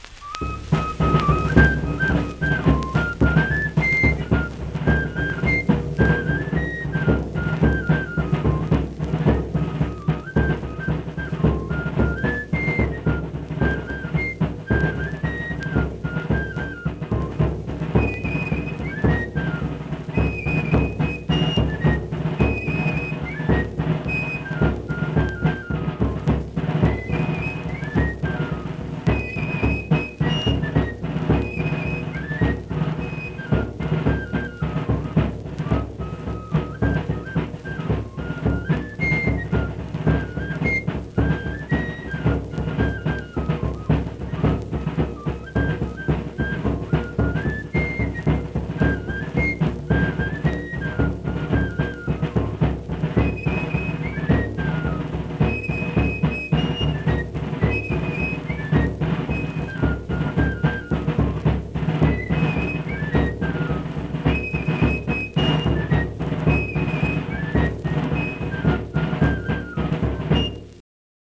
Accompanied fife and drum music
Performed in Hundred, Wetzel County, WV.
Instrumental Music
Fife, Drum